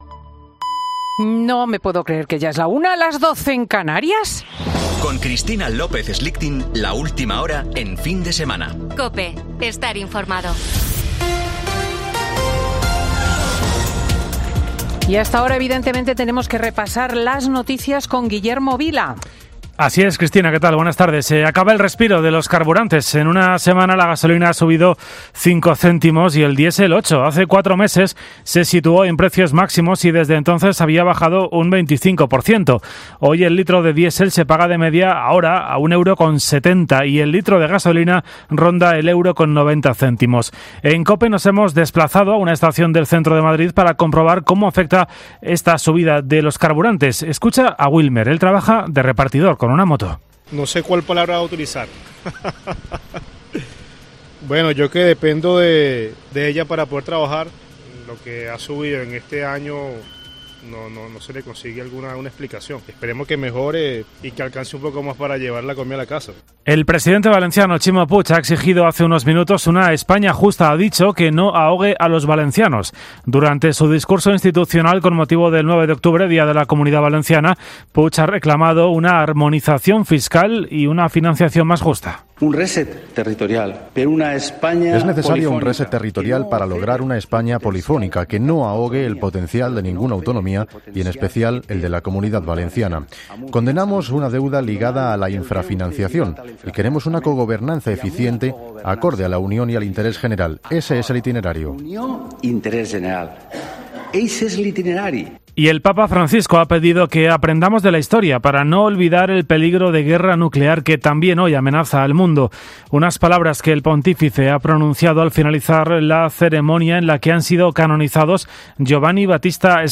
Boletín de noticias de COPE del 9 de octubre de 2022 a las 13.00 horas